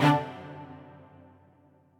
stringsfx2_6.ogg